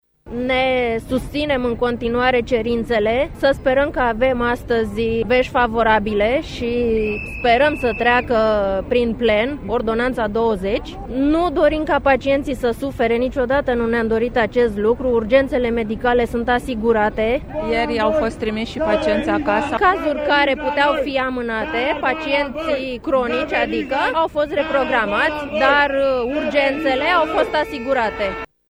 a stat de vorbă cu sindicaliştii aflaţi în faţa spitalului Floresca din Capitală: